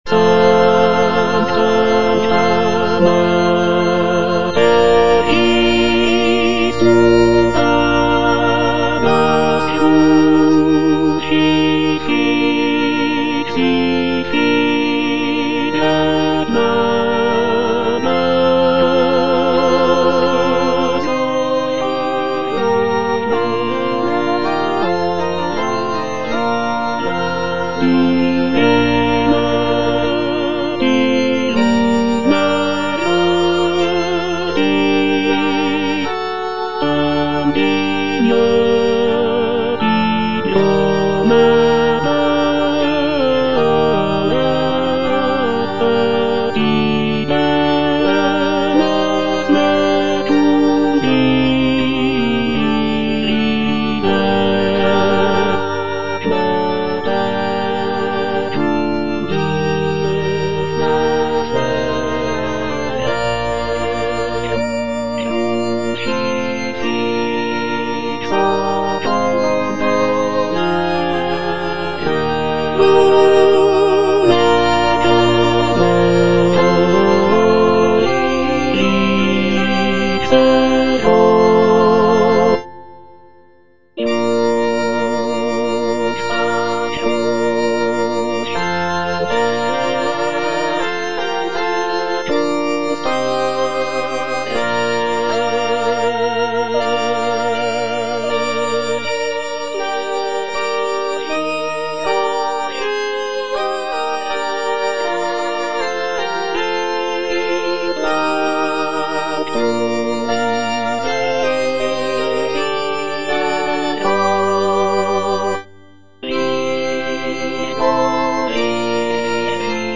G.P. DA PALESTRINA - STABAT MATER Sancta Mater, istud agas (tenor I) (Emphasised voice and other voices) Ads stop: auto-stop
sacred choral work